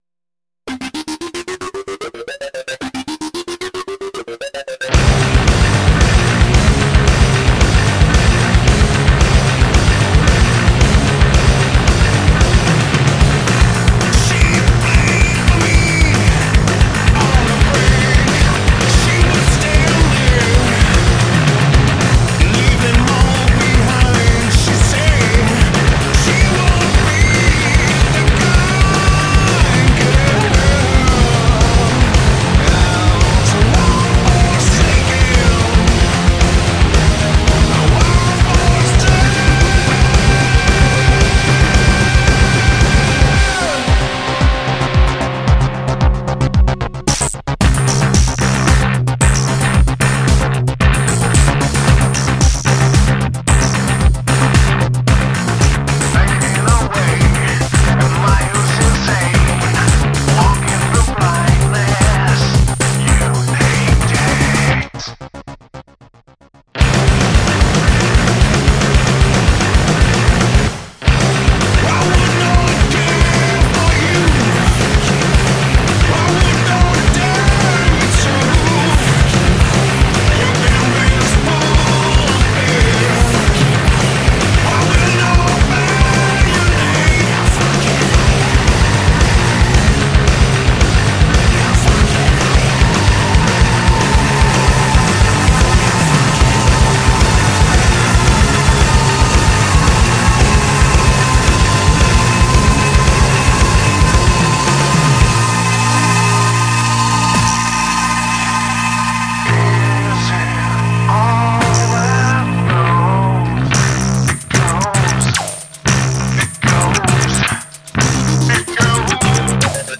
vokal
kitara
bobni